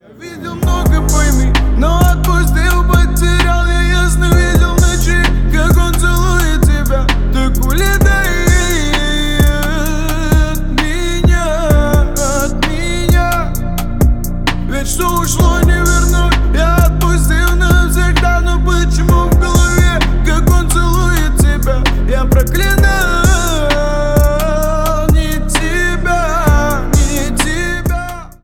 • Качество: 320, Stereo
мужской голос
грустные
русский рэп
спокойные
качающие